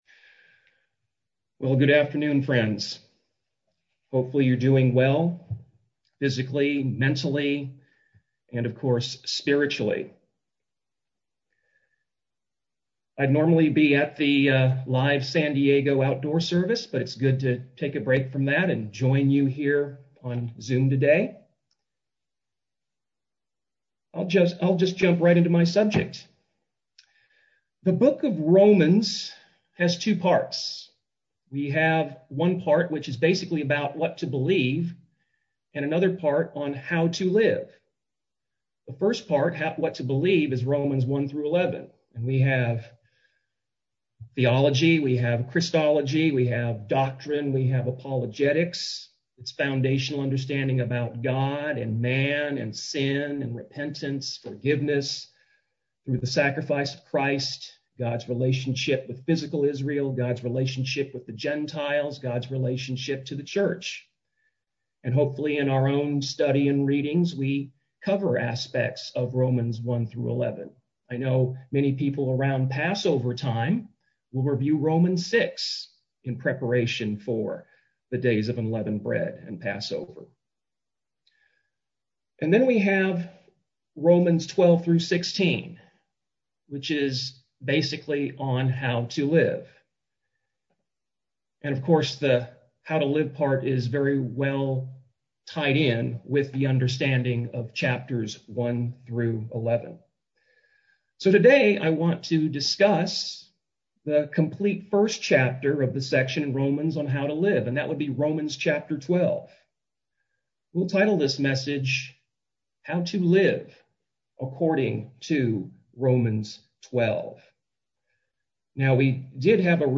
In this sermon, we go through the first chapter of the "how to live" section--Romans 12.